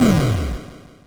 snd_screenshake.wav